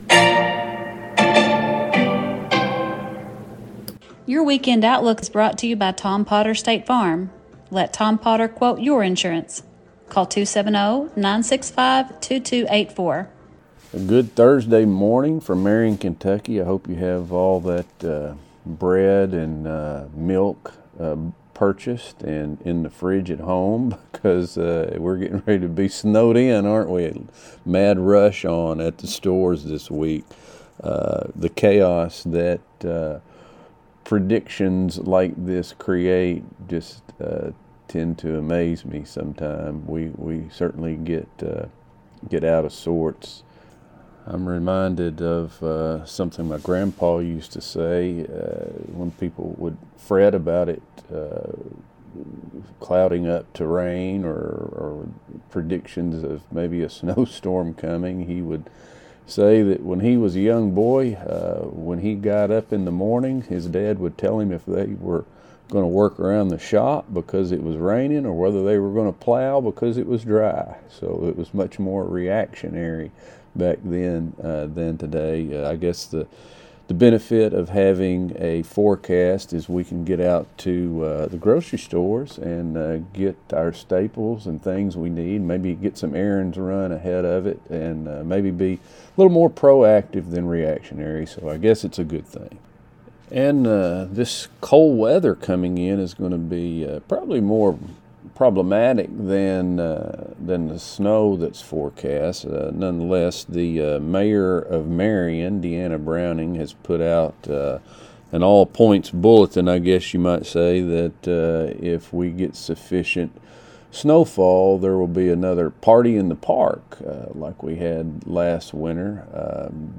STATE FARM | THURSDAY NEWScast